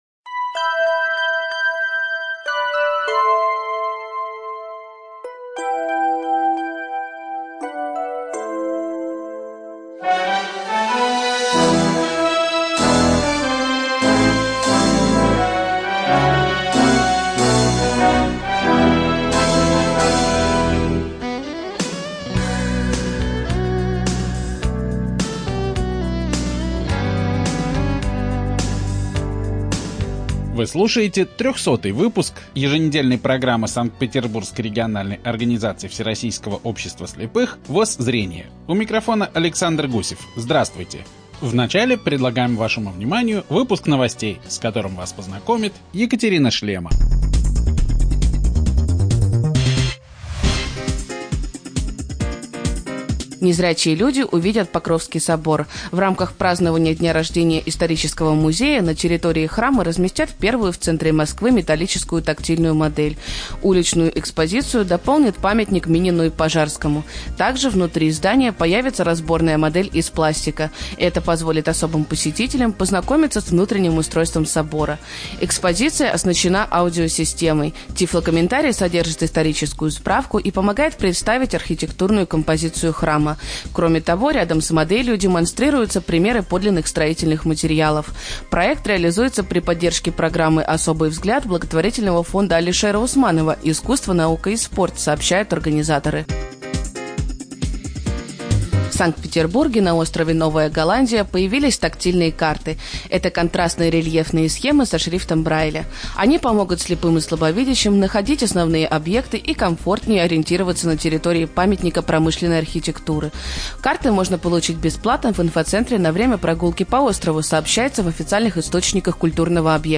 ЖанрРеабилитация, Радиопрограммы